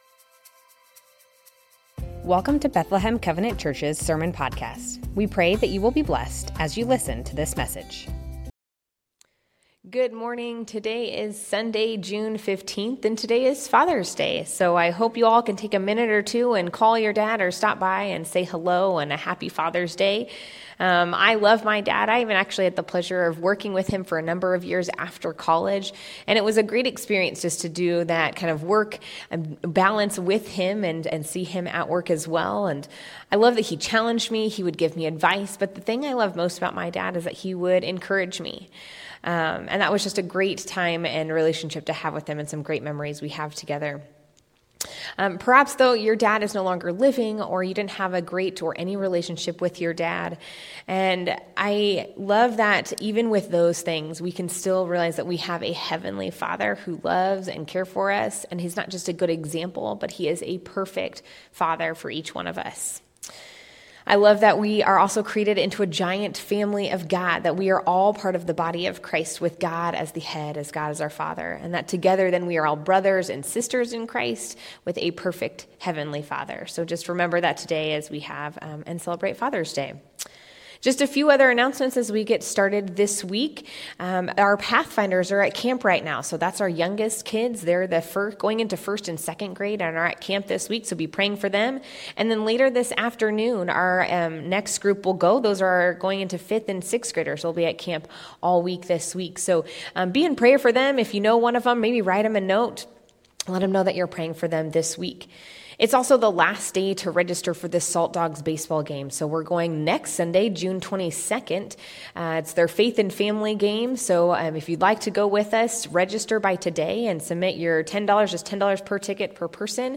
Bethlehem Covenant Church Sermons Matthew 18:1-14 - The Little Ones Jun 15 2025 | 00:25:46 1x 00:00 / 00:25:46 Subscribe Share Spotify RSS Feed Share Link Embed